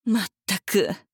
大人女性│女魔導師│リアクションボイス│商用利用可 フリーボイス素材 - freevoice4creators
困る